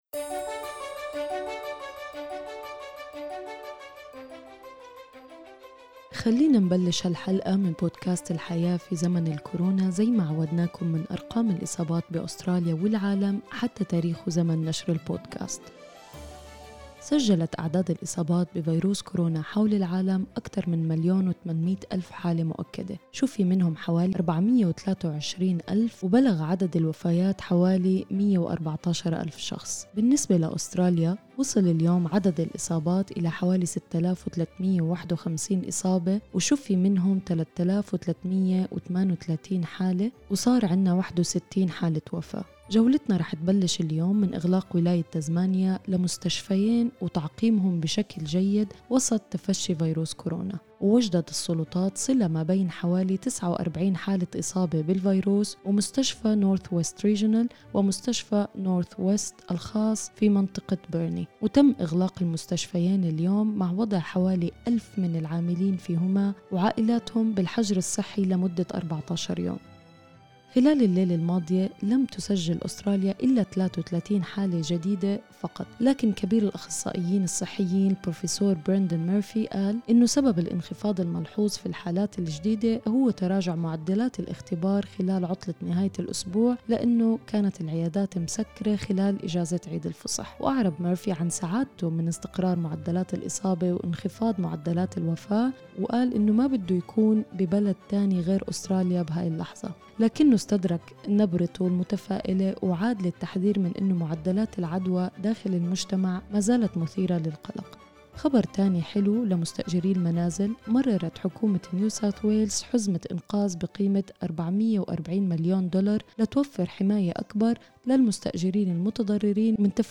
أخبار الكورونا اليوم 13/4/2020